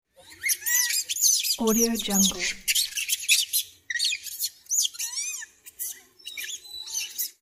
Small Monkeys Bouton sonore